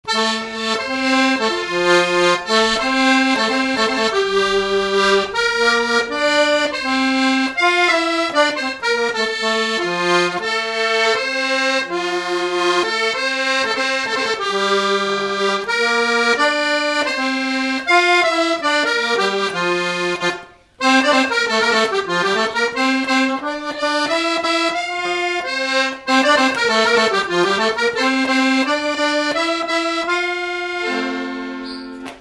Marche de cortège de noce
Résumé instrumental
circonstance : fiançaille, noce
Pièce musicale inédite